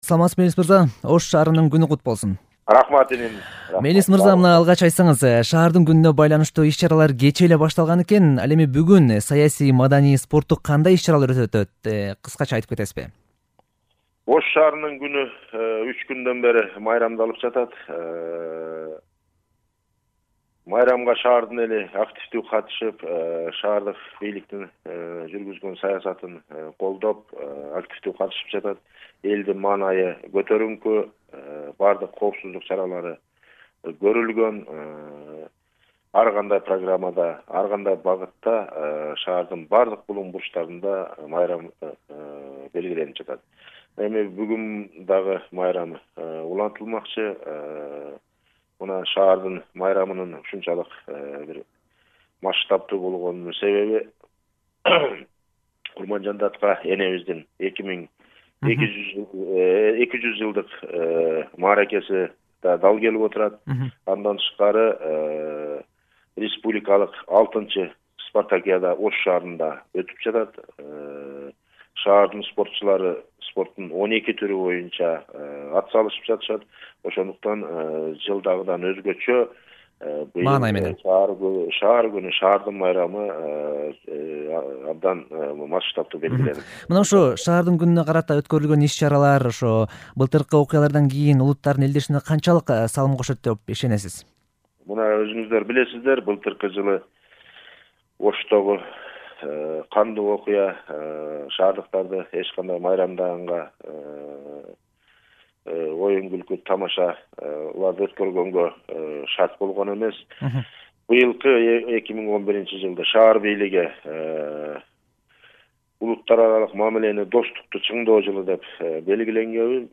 Ош мэри Мелис Мырзакматов менен маек